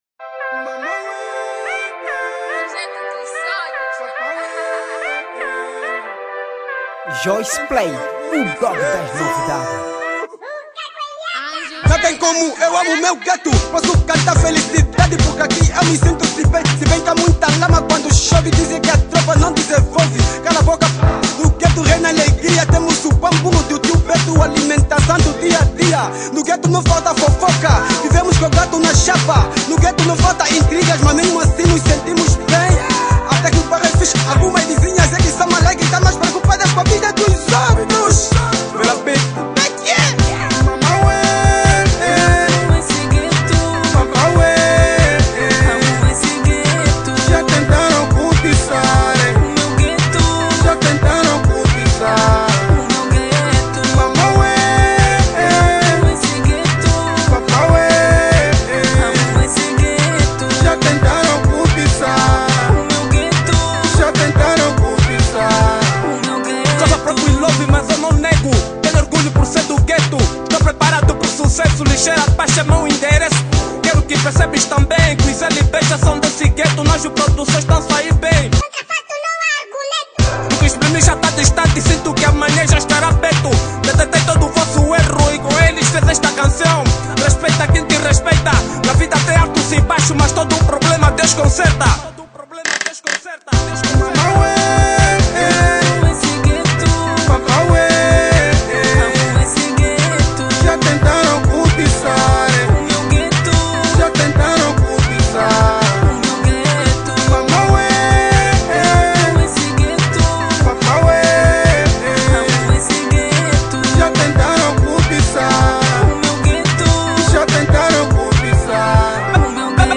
Categoria: Kuduro